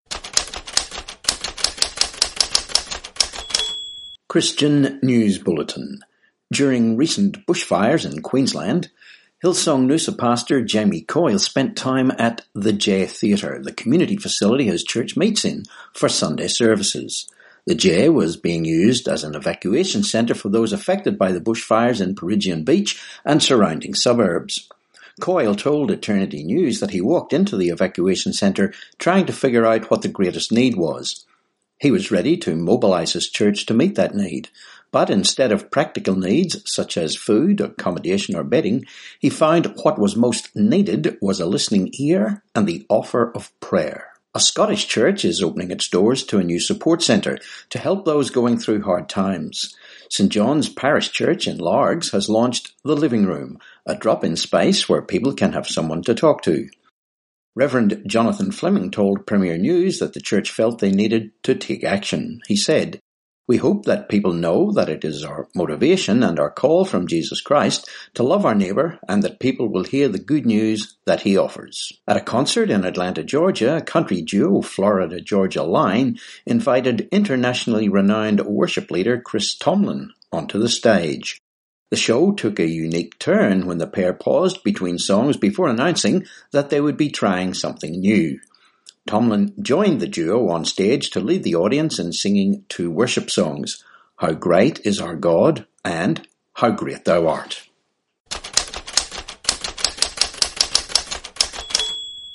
29Sep19 Christian News Bulletin